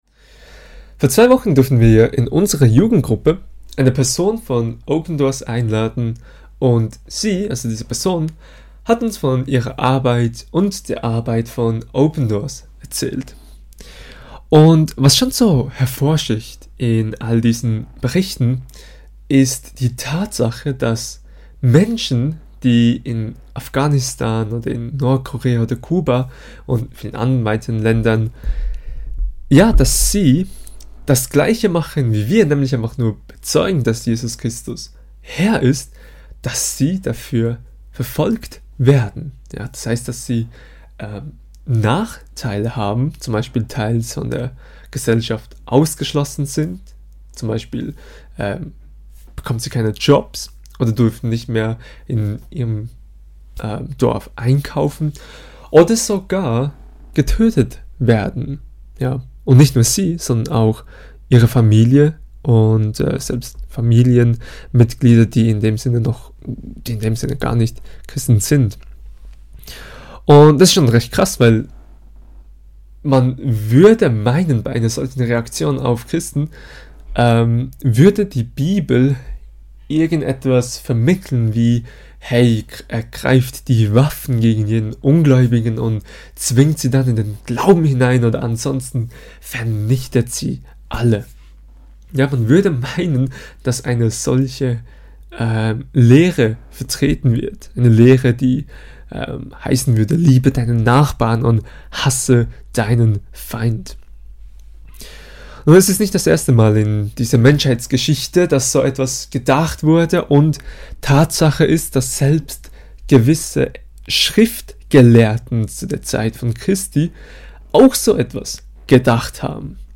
Kategorie: Bibelstunde